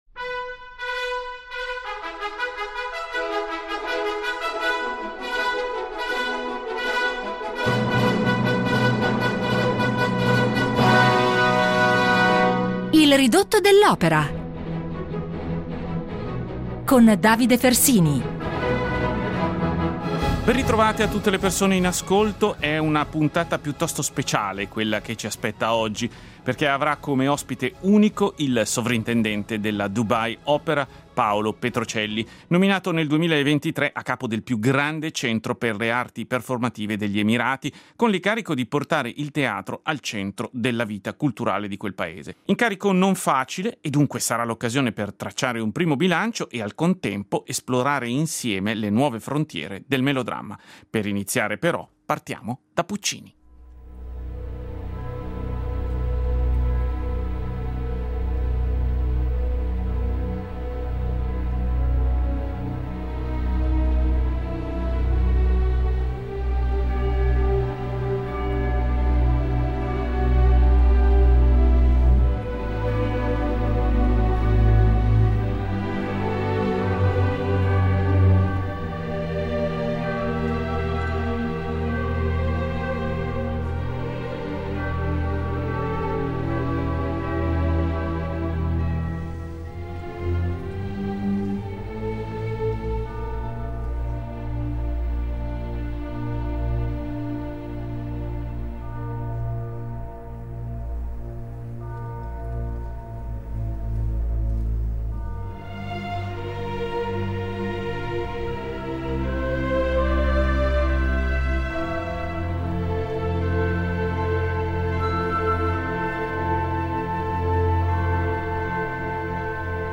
In dialogo